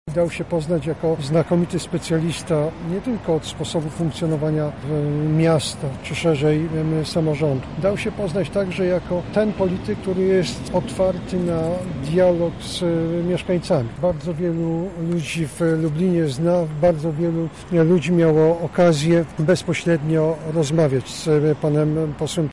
Gowin – mówi Jarosław Gowin, minister nauki i szkolnictwa wyższego.